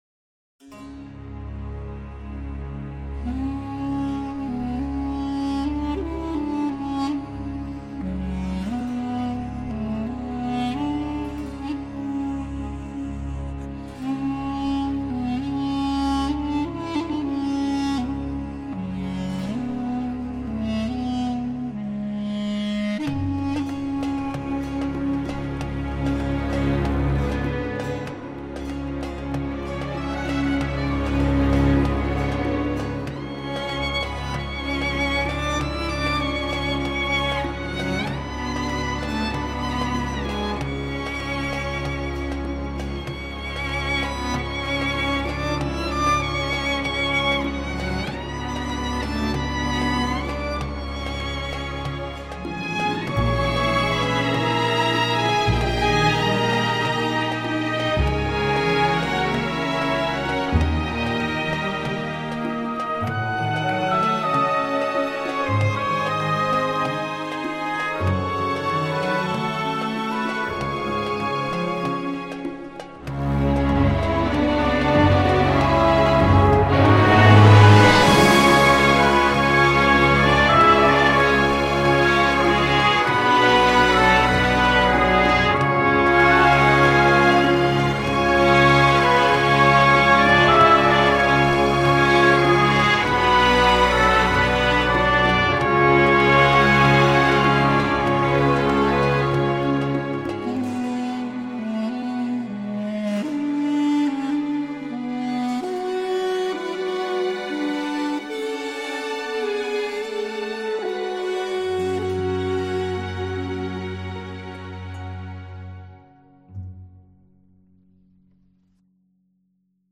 描述：电影|激越
Tag: 弦乐器 Horn